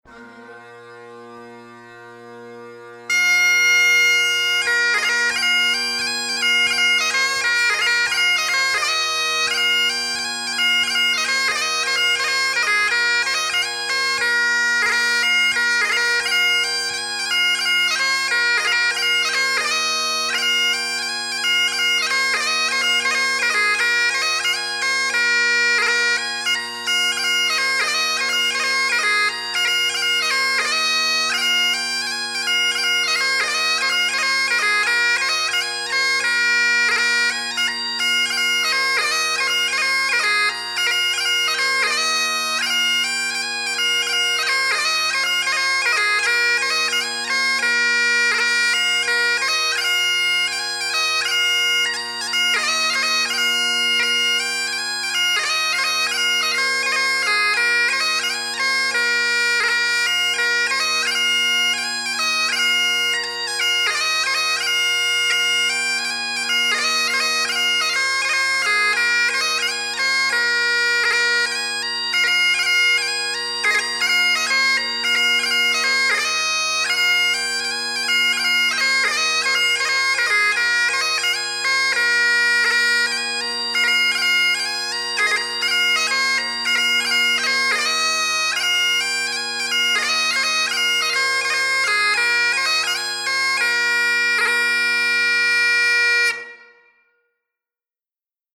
Scottish and Irish Bagpipe Music
Cameron MacFadyen – 6/8 March